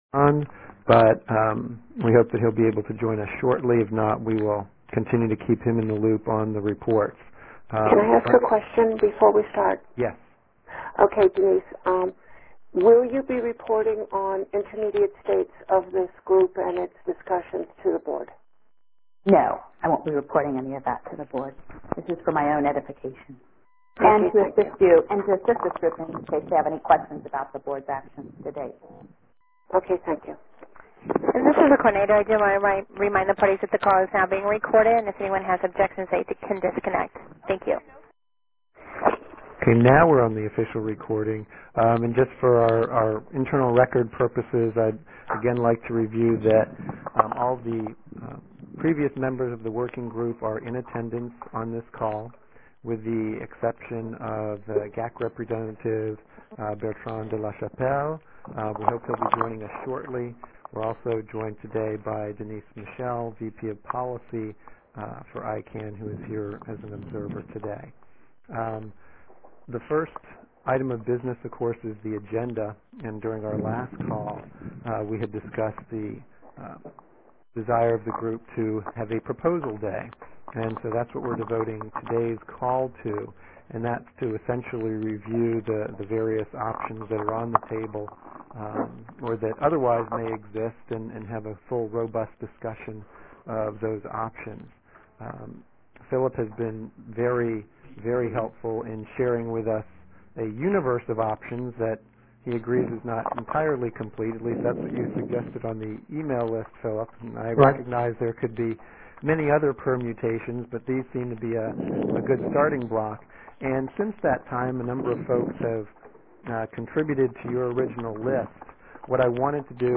[gnso-consensus-wg] MP3 recording of the GNSO consensus call 11 July 2008